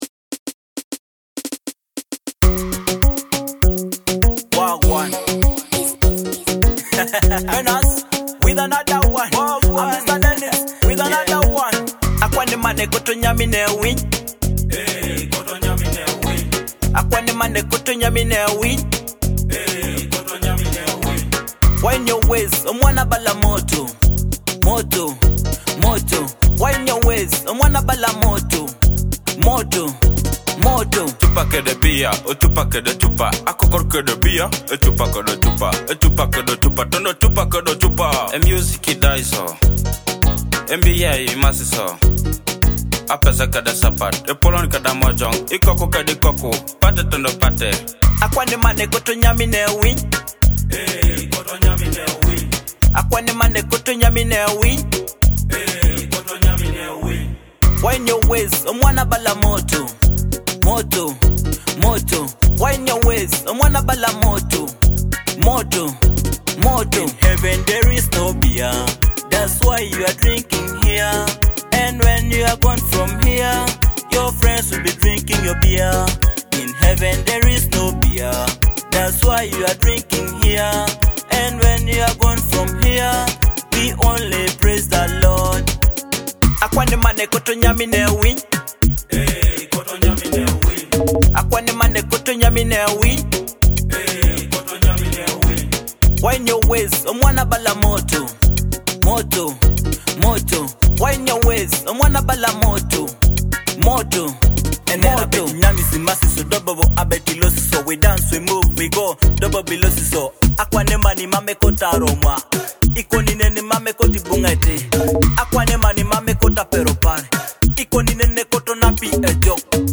energetic track